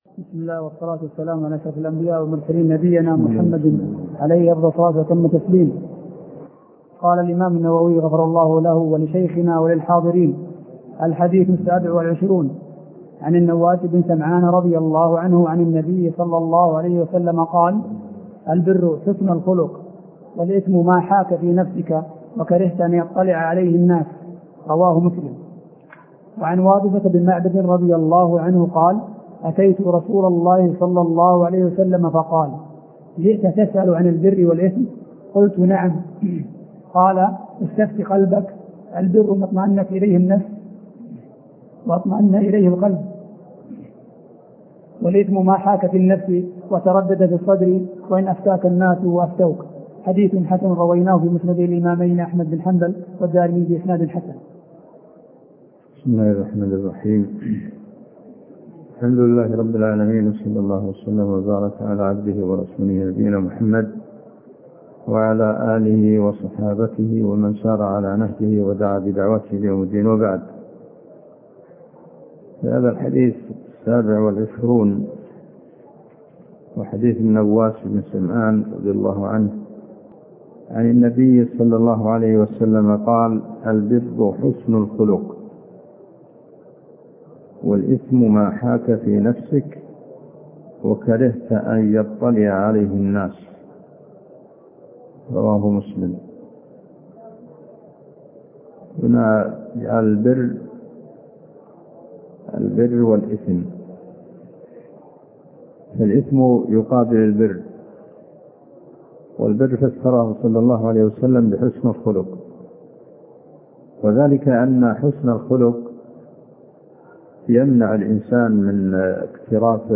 عنوان المادة الدرس (18) شرح الأربعين النووية تاريخ التحميل الأحد 22 يناير 2023 مـ حجم المادة 20.86 ميجا بايت عدد الزيارات 156 زيارة عدد مرات الحفظ 82 مرة إستماع المادة حفظ المادة اضف تعليقك أرسل لصديق